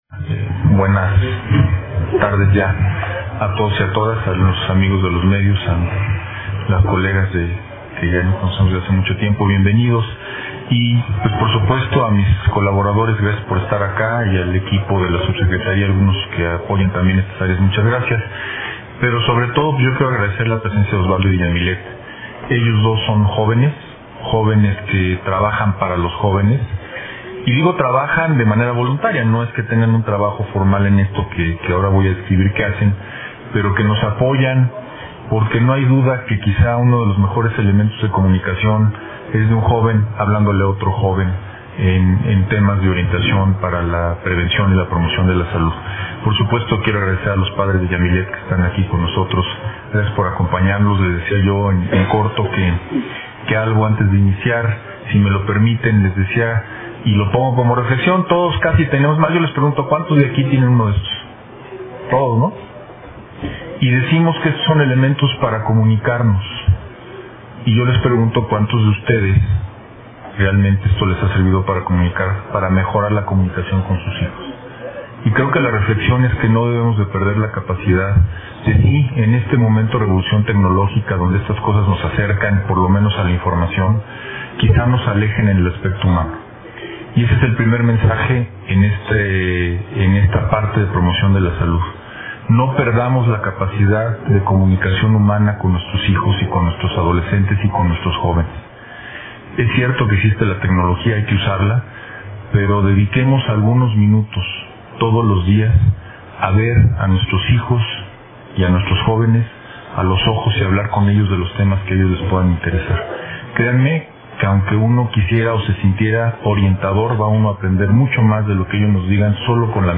En conferencia de prensa, el doctor Kuri Morales destacó la importancia de estas medidas e invitó a los jóvenes a participar en las actividades que se realizan en los servicios de salud.